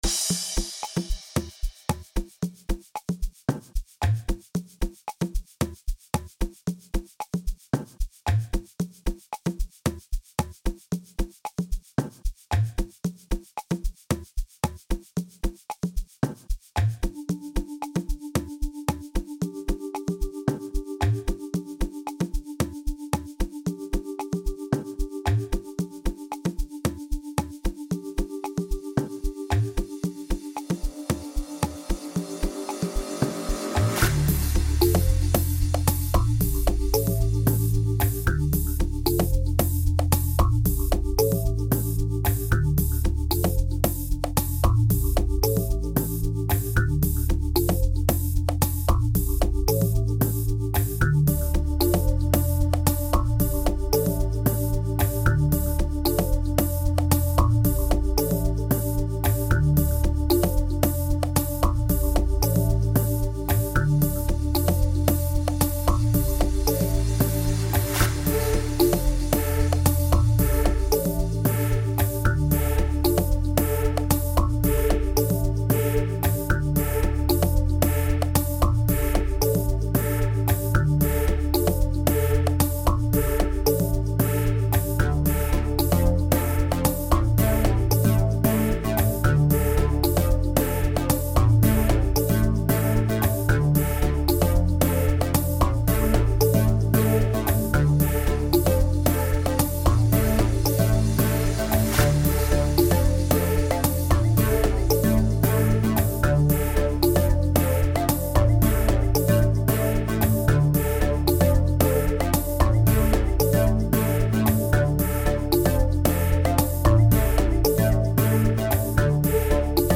soulful record